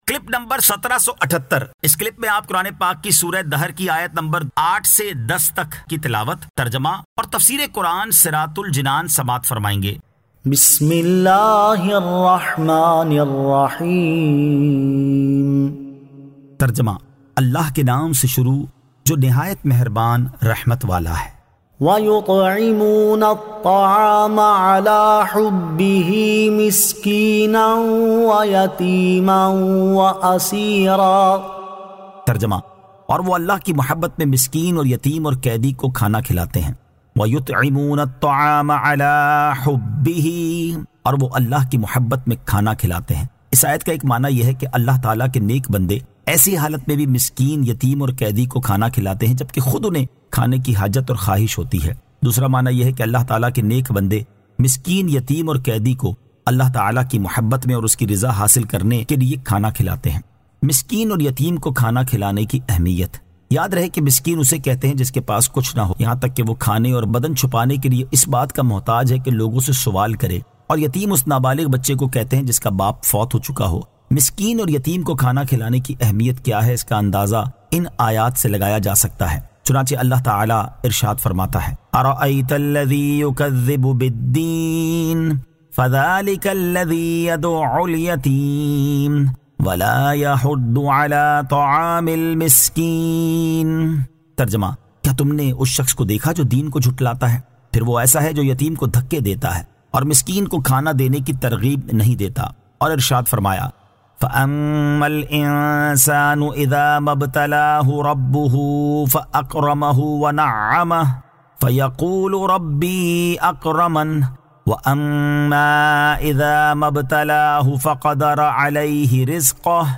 Surah Ad-Dahr 08 To 10 Tilawat , Tarjama , Tafseer